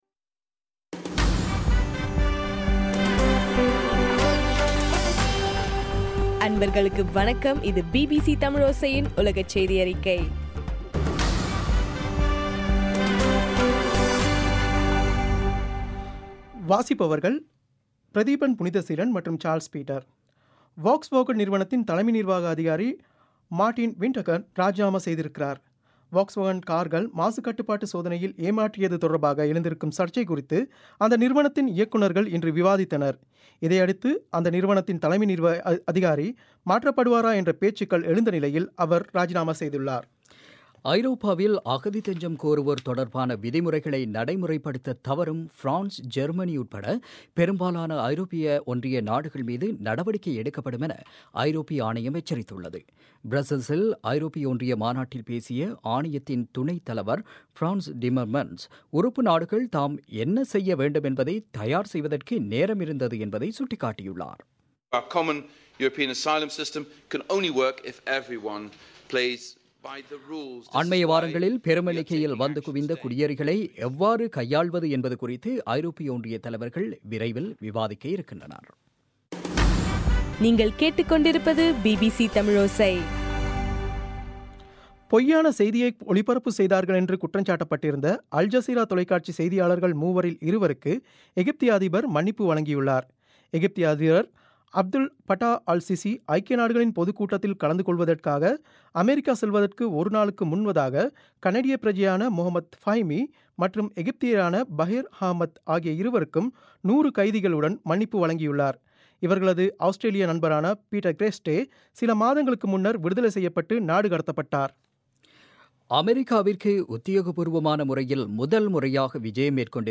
செப்டம்பர் 23 பிபிசியின் உலகச் செய்திகள்